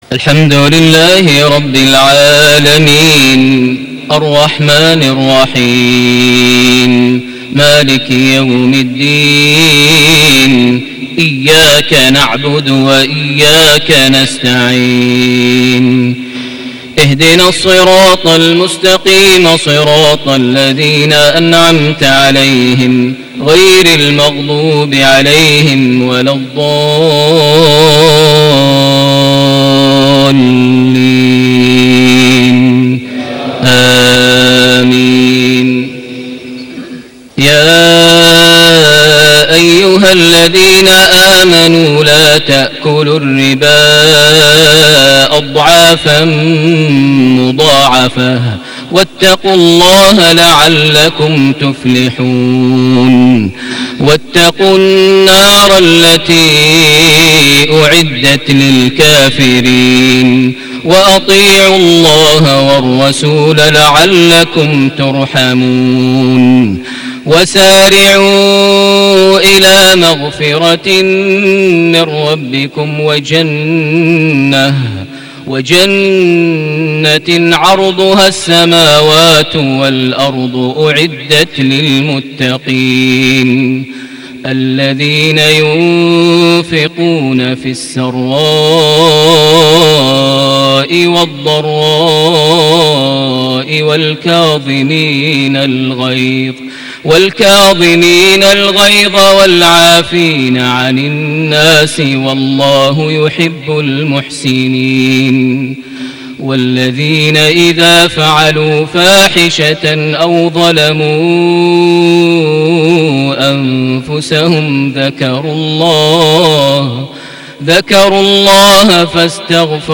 صلاة العشاء ١ رجب ١٤٣٨هـ سورة آل عمران ١٣٠-١٤٥ > 1438 هـ > الفروض - تلاوات ماهر المعيقلي